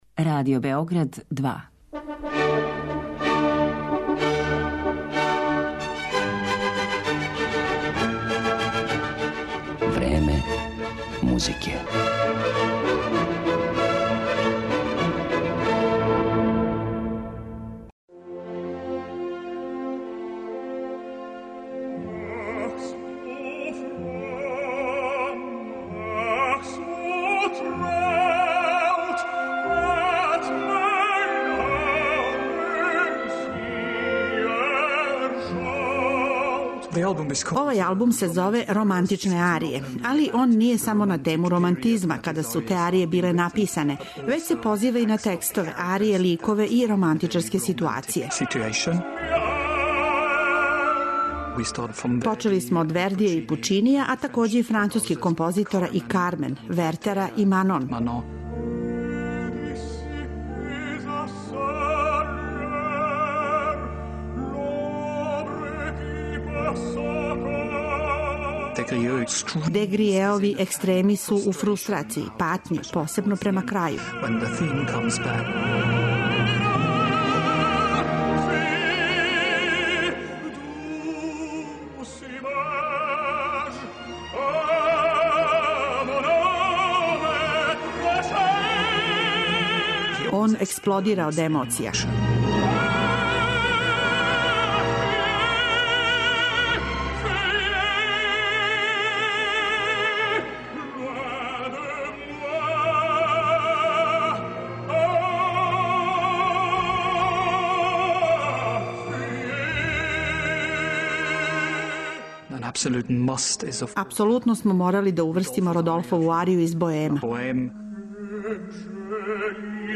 Његов необичан таман, снажан и покретљив глас, као и суптилна глума и висока музикалност, омогућили су му да са успехом изводи и снима углавном романтичарски репертоар, од Вердија, композитора веризма и Пучинија, до Вагнера, Бизеа и Маснеа.